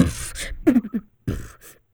04 RSS-VOX.wav